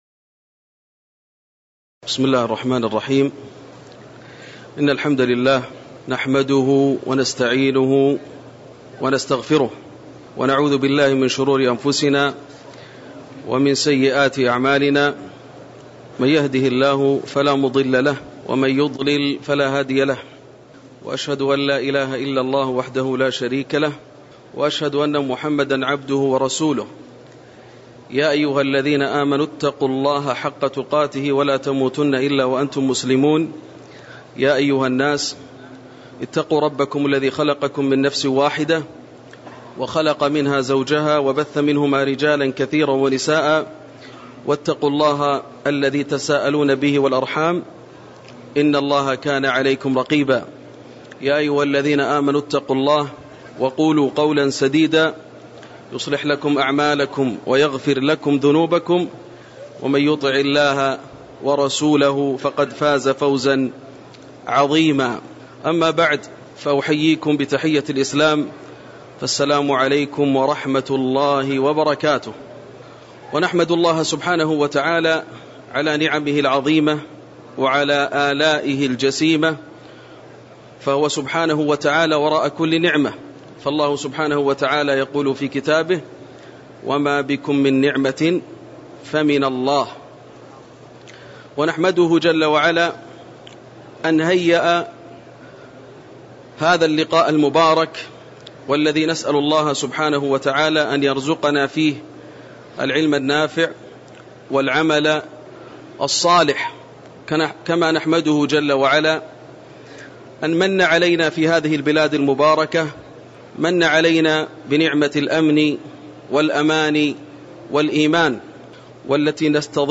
تاريخ النشر ٢٢ ذو القعدة ١٤٣٩ هـ المكان: المسجد النبوي الشيخ: فضيلة الشيخ ياسر الدوسري فضيلة الشيخ ياسر الدوسري مقاصد الحج في ضوء القرآن والسنة (01)